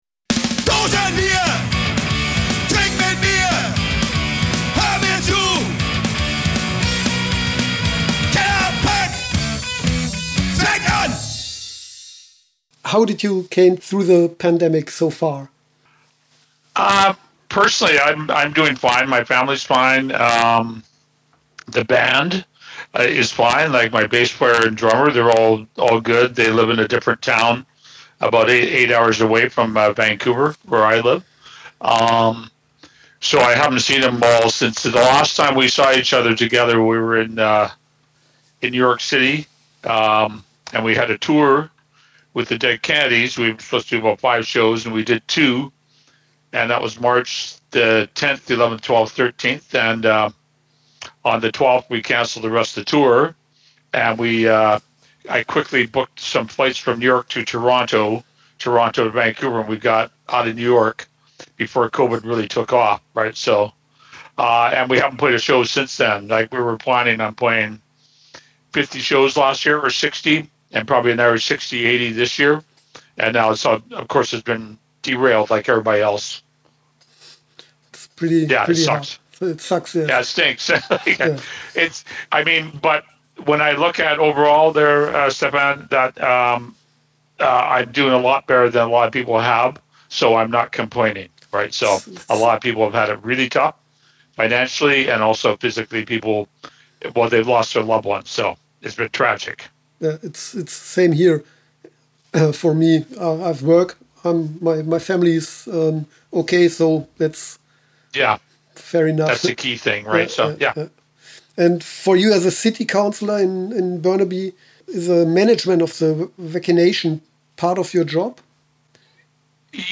Letzte Episode Kellerpunk mit Joe Keithley (D.O.A.) – part 1 2. April 2021 Nächste Episode download Beschreibung Teilen Abonnieren Letzte Woche hatte ich die Gelegenheit mit Joe Keithley, dem Sänger der kanadischen Punk-Legende D.O.A. zu sprechen.